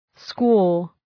Shkrimi fonetik {skwɔ:}